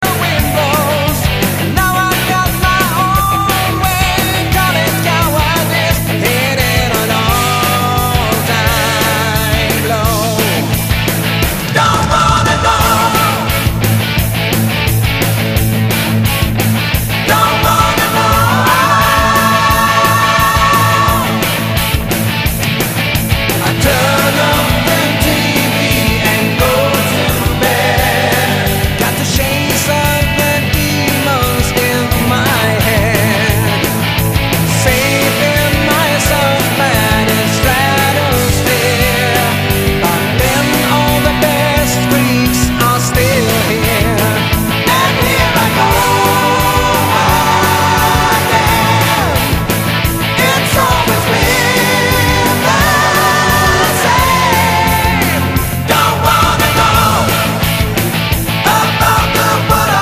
massive big background vocals.
keyboards, bass, guitars
lead and background vocals
drums
acoustic guitar
guitar solos